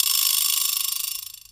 TSW VIBRA.wav